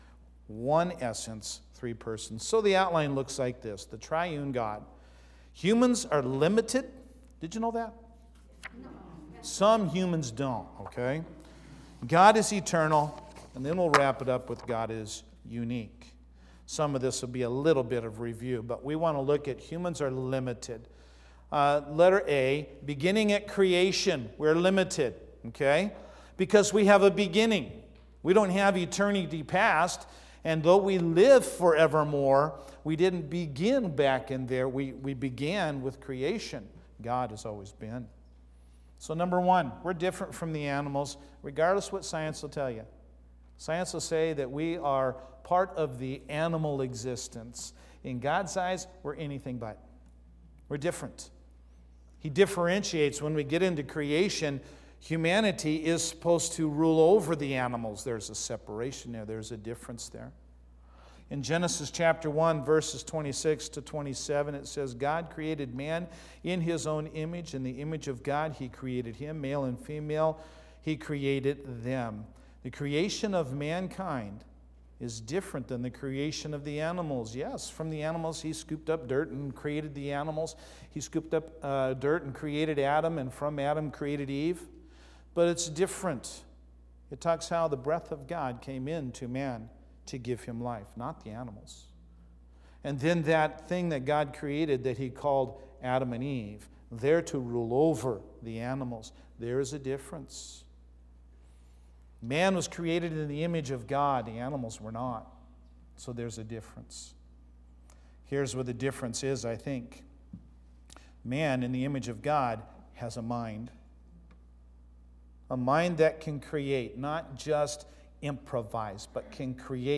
Sermons - North Mason Bible Church - Page 28